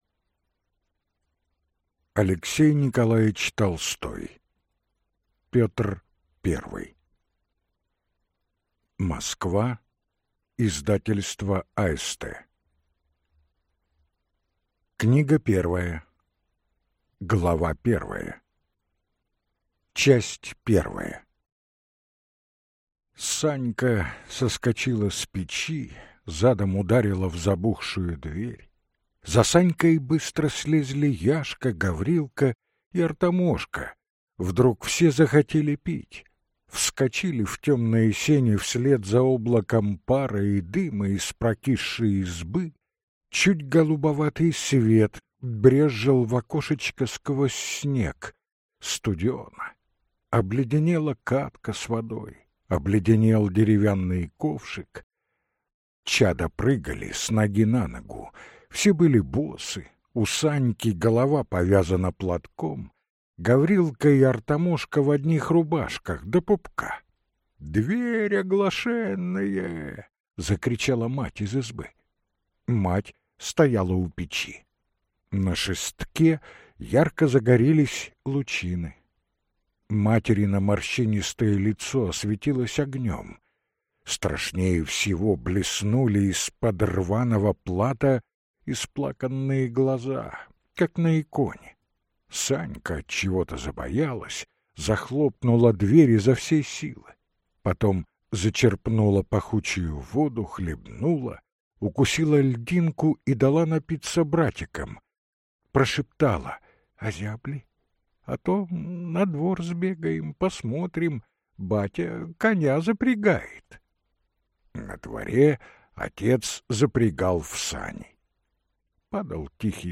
Аудиокнига Петр Первый (Книга 1) | Библиотека аудиокниг